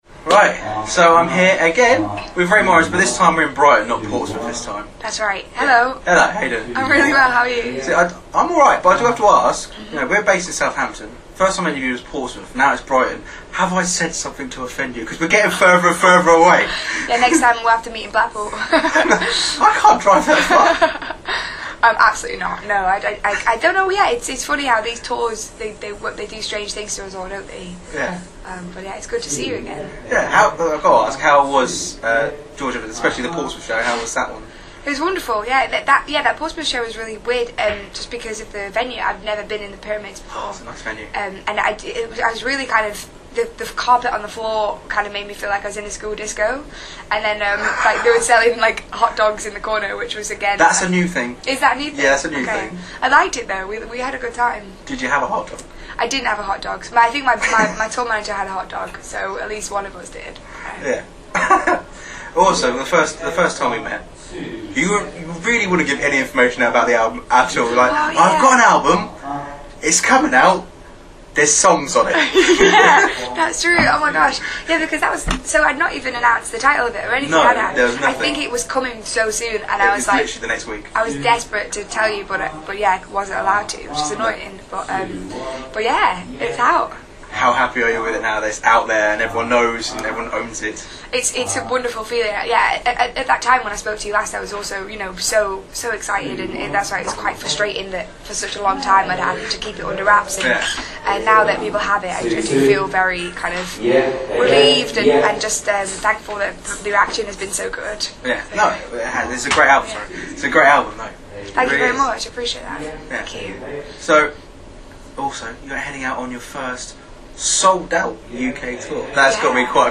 Rae Morris interview Feb 2015